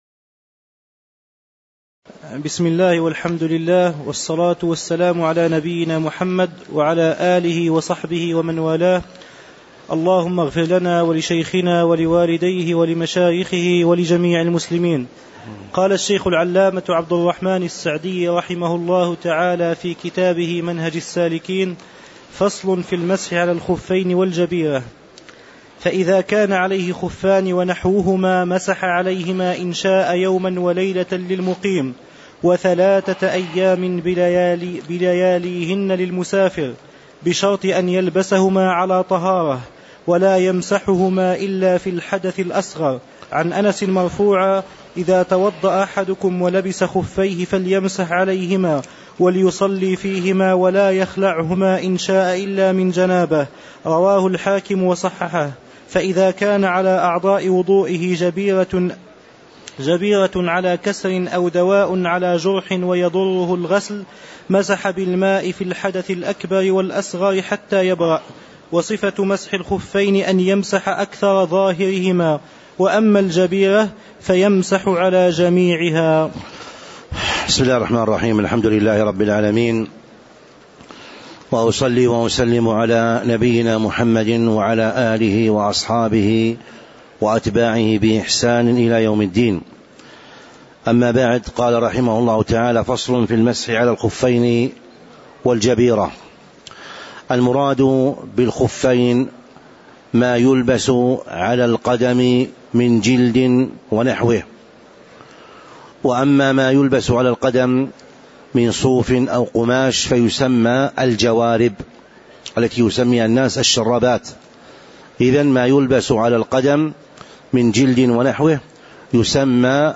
تاريخ النشر ٨ ربيع الأول ١٤٤٥ هـ المكان: المسجد النبوي الشيخ